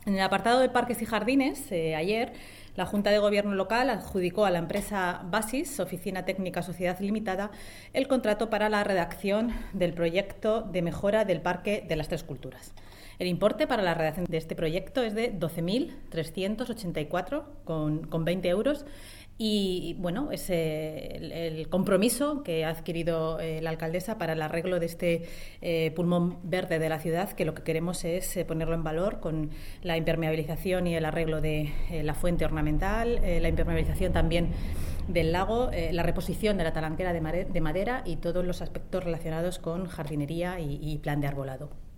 La portavoz del Gobierno local, Noelia de la Cruz, ha informado este jueves en rueda de prensa de los principales acuerdos adoptados en el seno de la última Junta de Gobierno Local donde se ha adjudicado, entre otros, el contrato para la redacción del proyecto de mejora del Parque de las Tres Culturas.